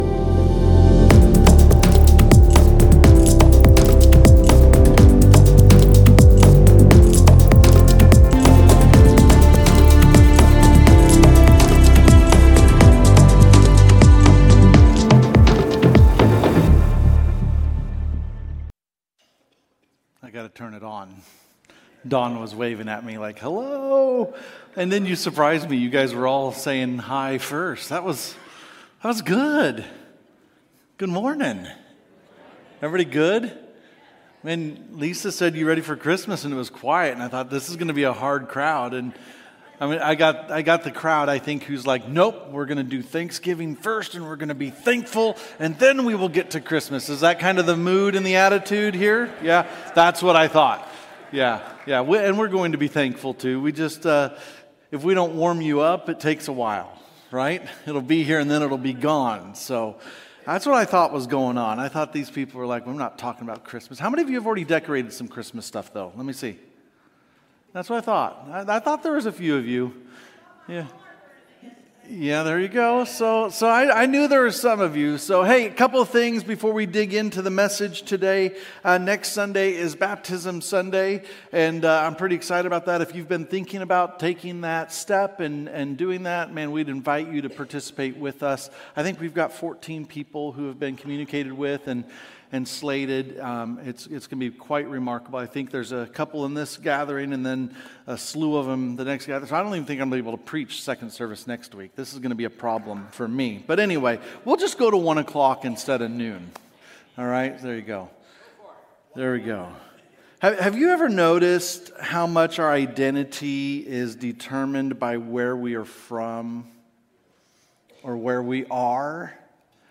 Sermons | Mountainview Christian Church